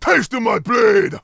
Hero Lines